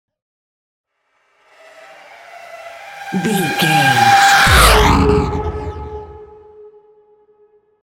Sci fi vehicle whoosh large
Sound Effects
dark
futuristic
intense
whoosh